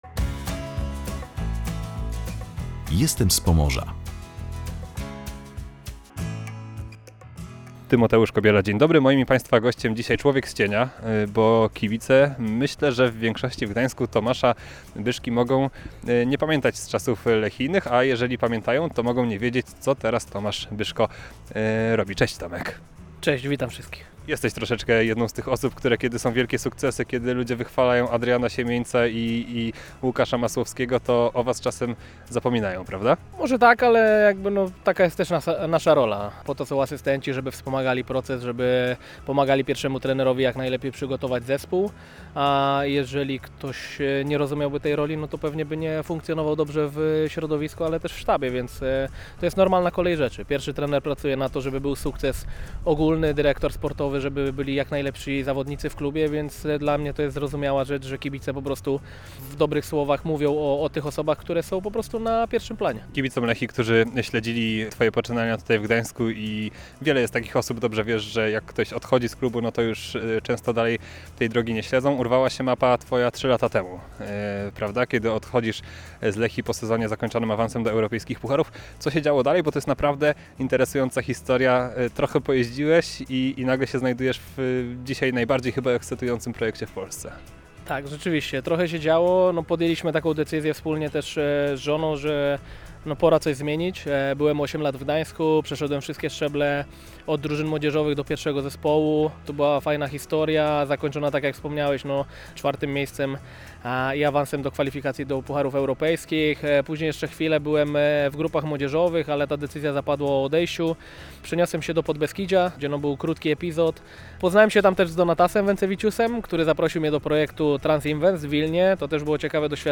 O jego drodze, zwątpieniach, trudnych momentach i tych najpiękniejszych rozmawiamy w audycji Jestem z Pomorza.